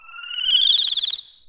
TECHFX  57.wav